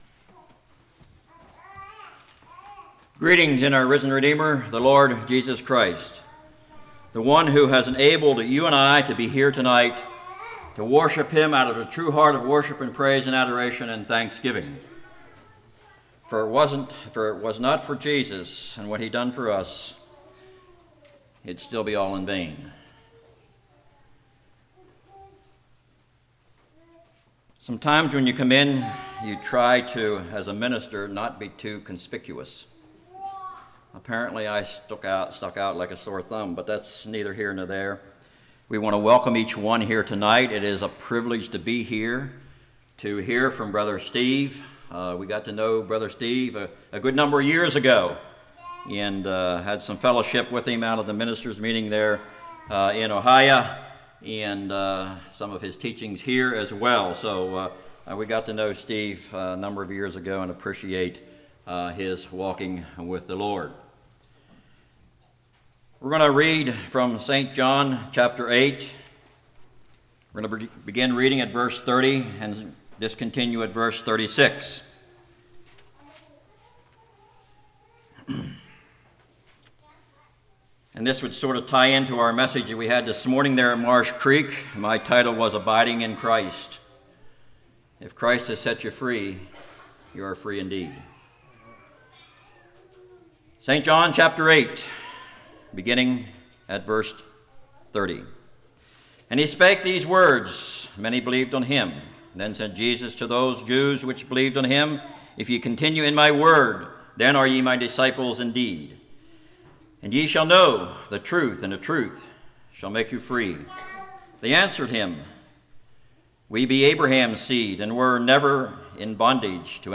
John 8:30-36 Service Type: Revival Jesus calls us to count the cost